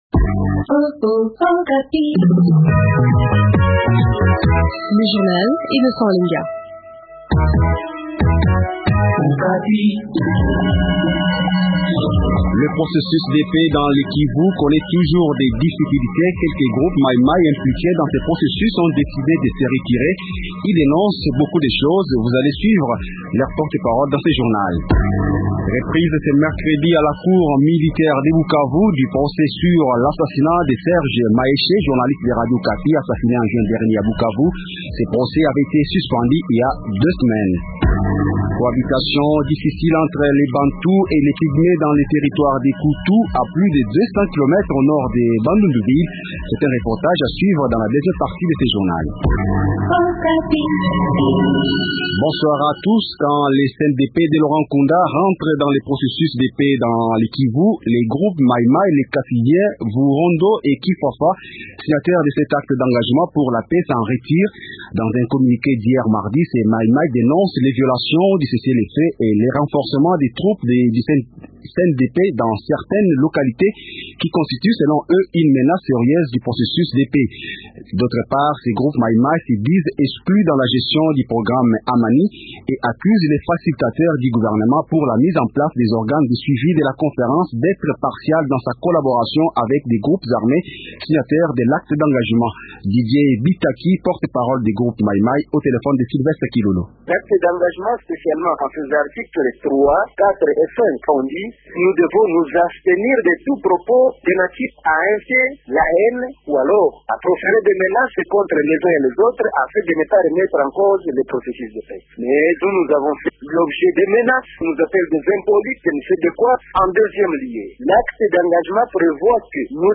Journal Francais Soir
Quelques groupes mai-mai impliqués dans ce processus ont décidé de se retirer. Ils dénoncent beaucoup de choses ; vous allez suivre leur porte-parole dans ce journal.
C’est un reportage à suivre dans la deuxième partie de notre journal.